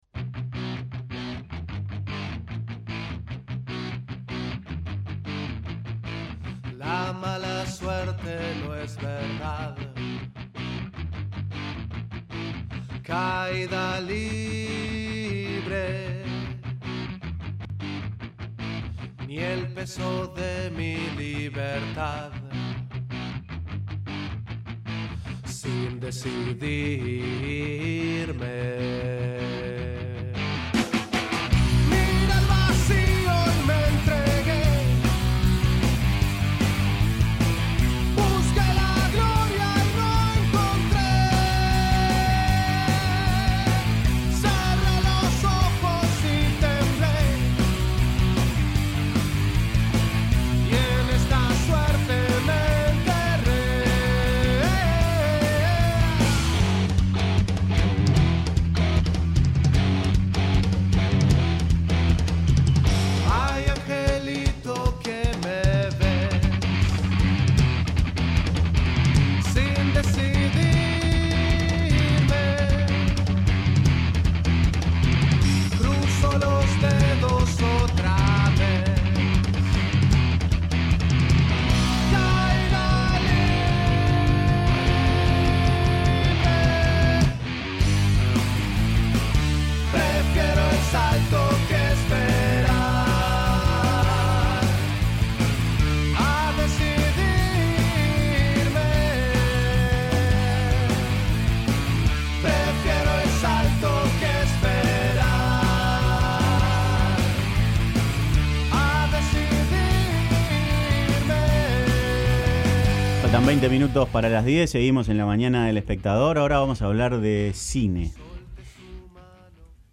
conversó con La Mañana de El Espectador sobre las particularidades de la historia de Chapecoense que lo llevaron a filmar el documental.